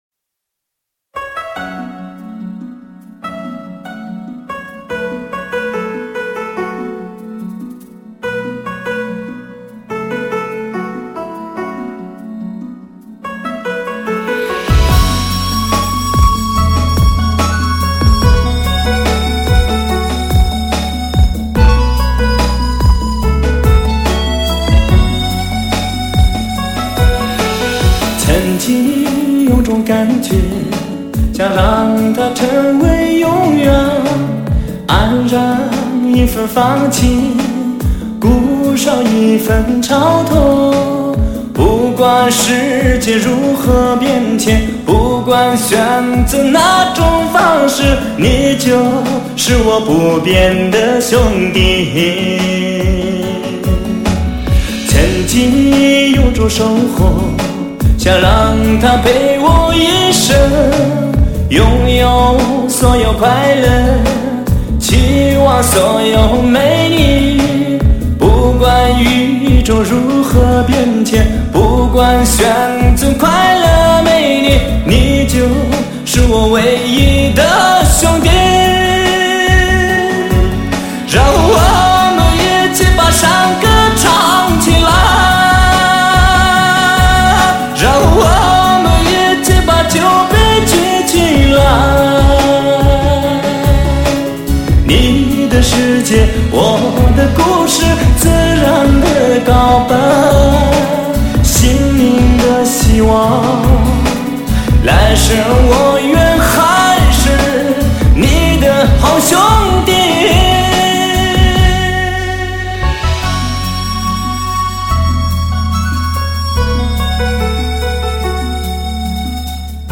为低音质MP3